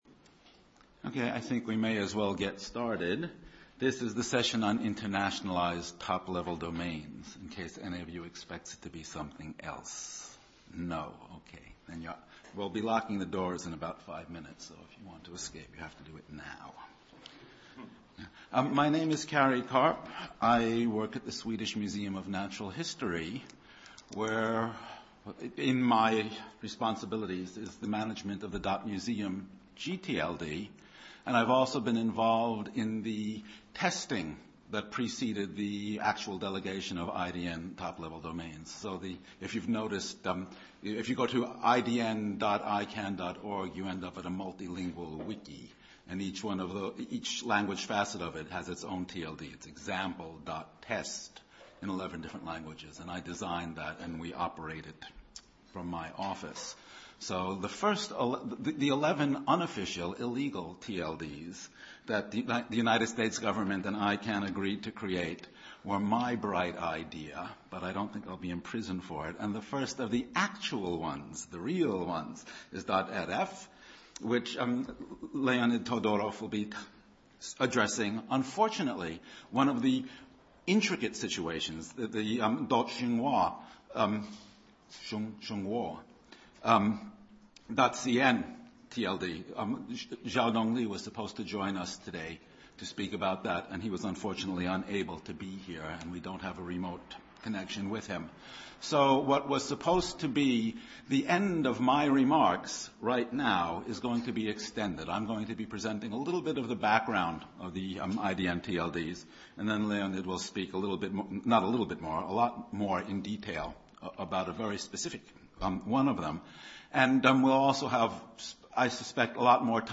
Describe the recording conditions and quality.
ICANN has opened the doors for internationalized top-level domains, and one of the first TLD’s to go live with this is .ru. At this seminar, we will hear about the Russian experiences as well as ICANN’s future plans.